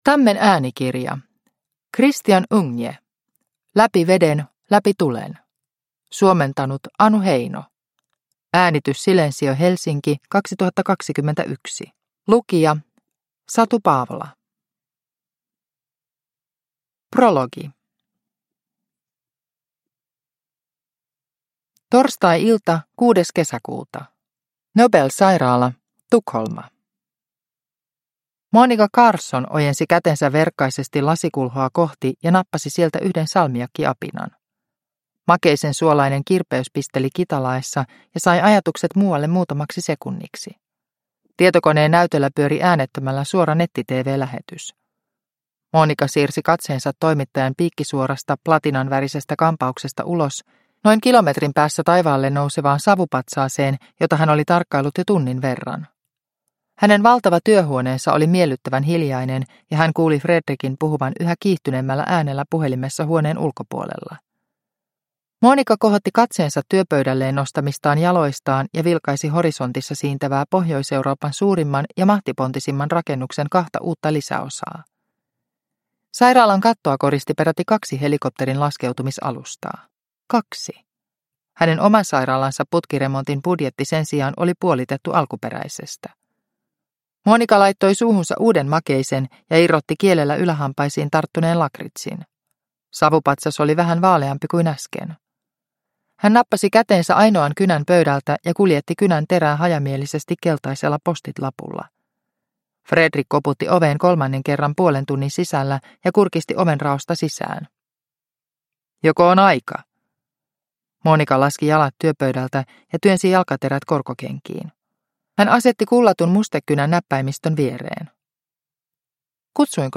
Läpi veden, läpi tulen – Ljudbok – Laddas ner